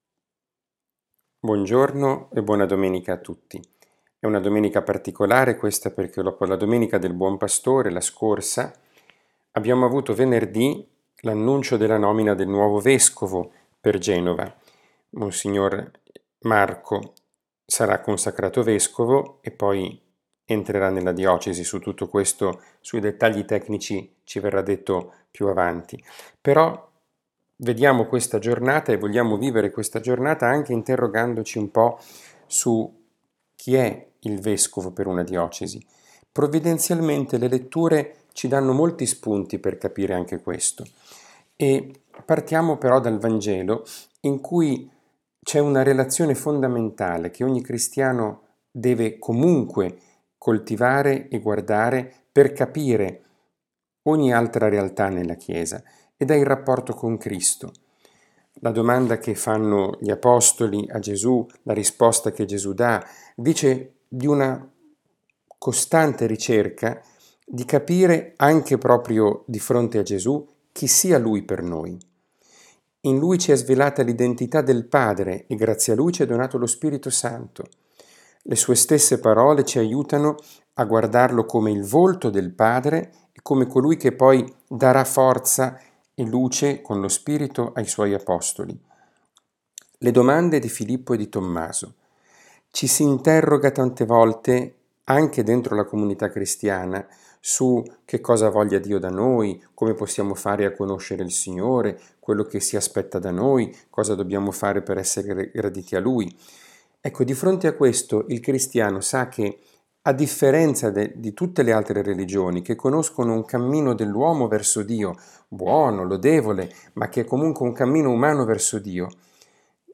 10 Maggio 2020 catechesi, Parola di Dio, podcast